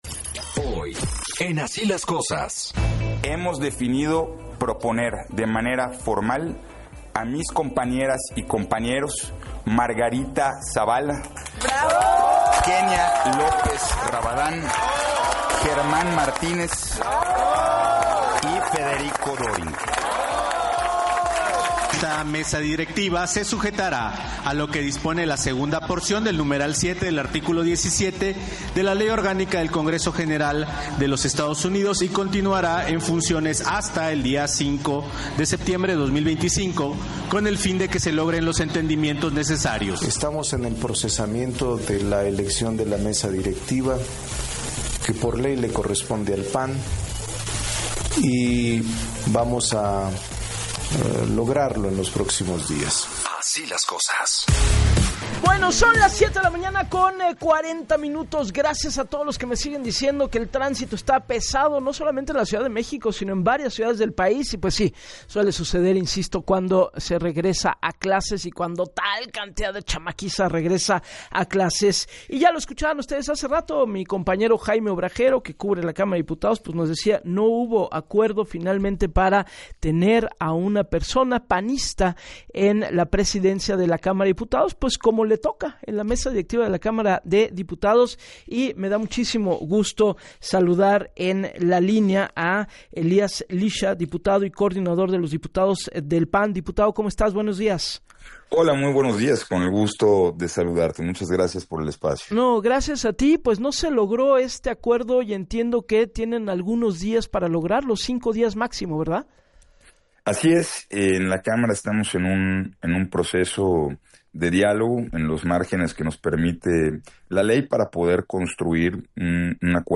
En entrevista con Gabriela Warkentin, el coordinador de los diputados del Partido Acción Nacional (PAN), Elías Lixa, informó que les corresponde presidir la Mesa Directiva en la Cámara de Diputados, es un hecho político y legal y esperan sea encabezada por una mujer, así lo han establecido a través de diálogos internos.